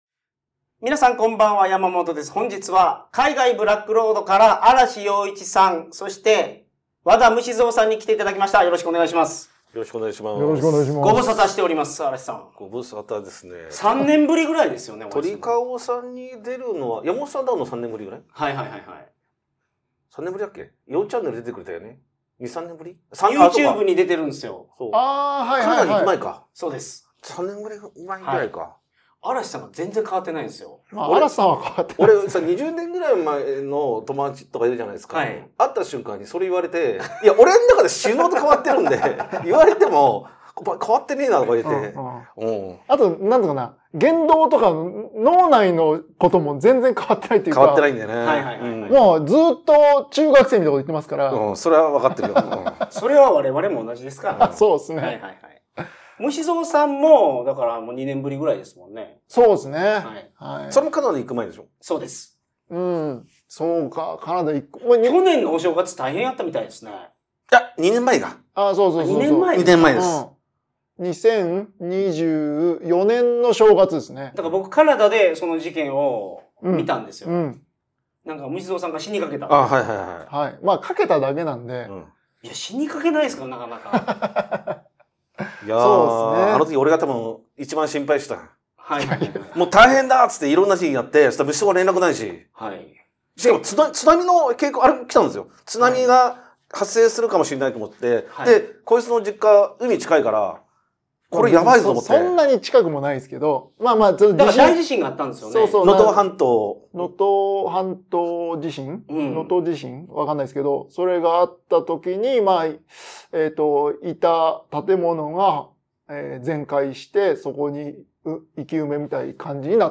Genres: Comedy, Comedy Interviews, Places & Travel, Society & Culture